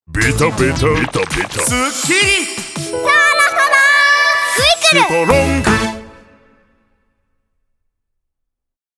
SONG ROCK / POPS